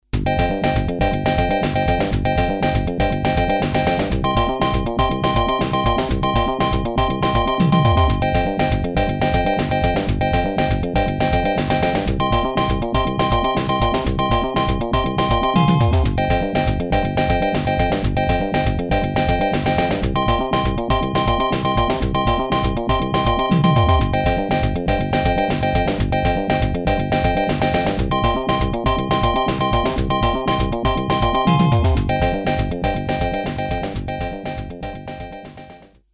「ＦＭ音源」
というのは嘘で、ＰＣ−９８２１からサルベージしてきたＦＭ音源＋ＳＳＧ音源の曲。